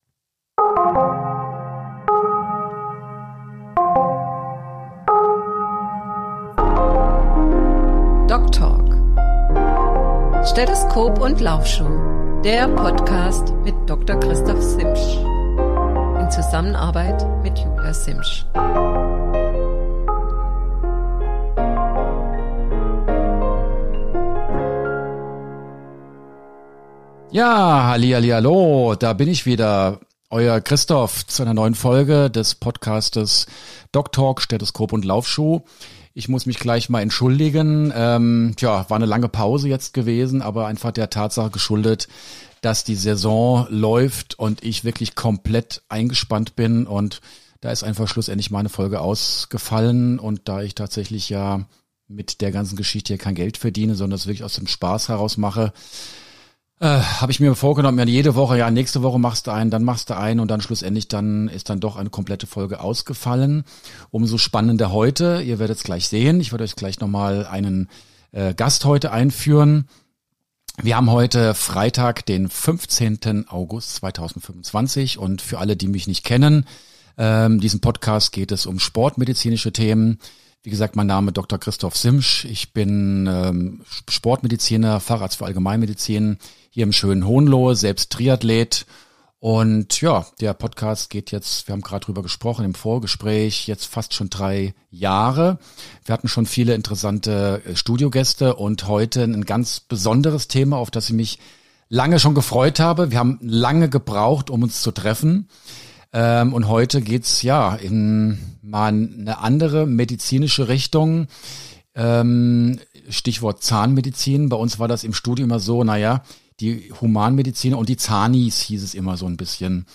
Endlich mal wieder ein Podcast im Dialog.